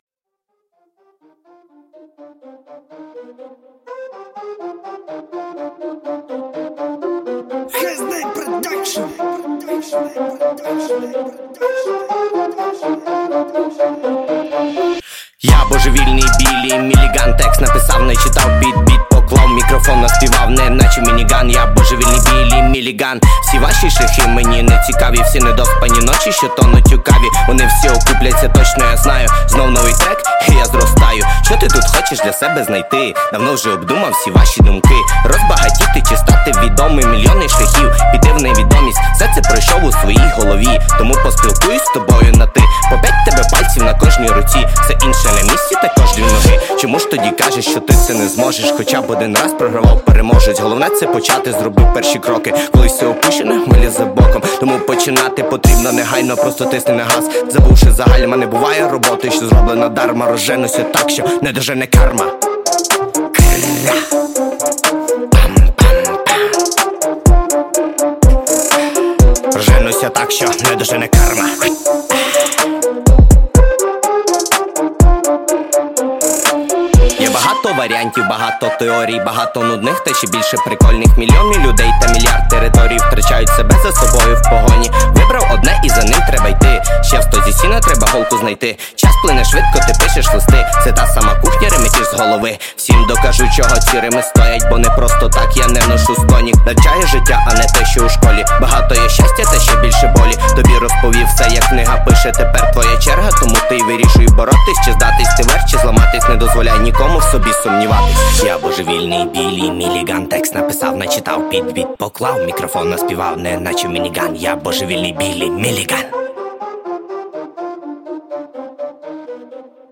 Баритон